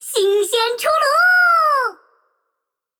击杀语音